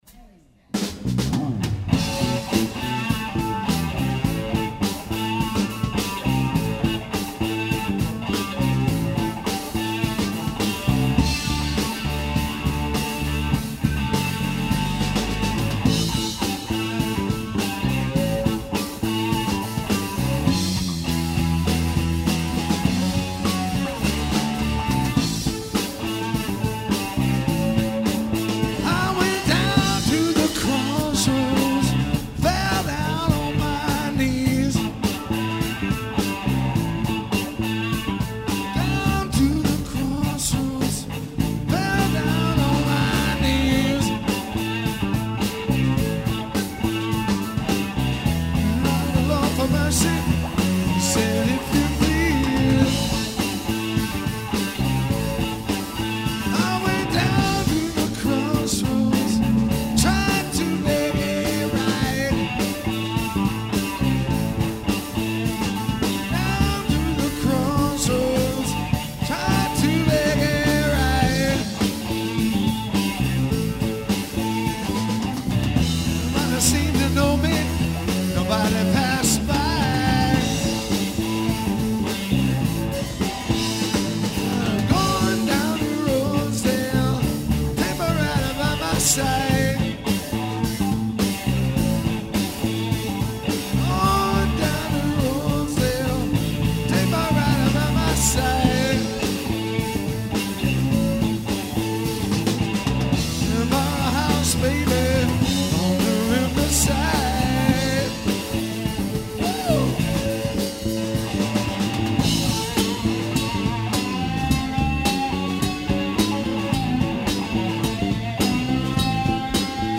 All these track were recorded LIVE!